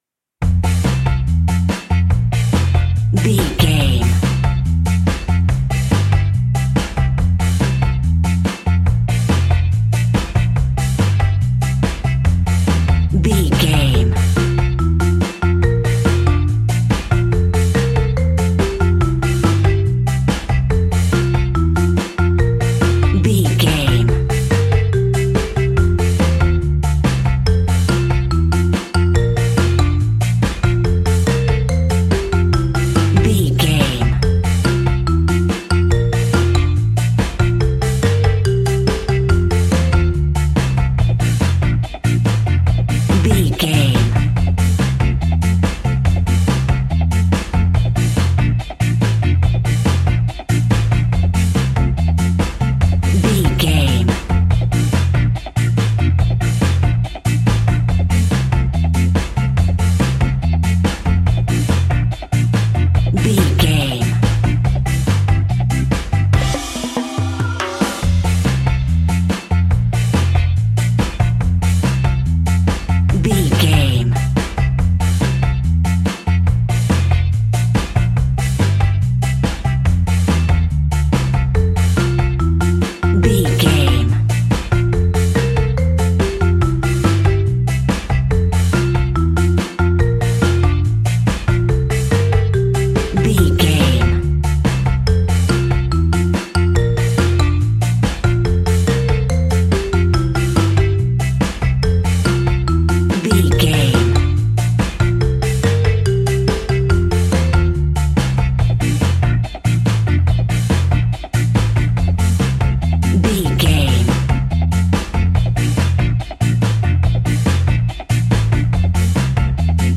Ionian/Major
dub
laid back
chilled
off beat
drums
skank guitar
hammond organ
transistor guitar
percussion
horns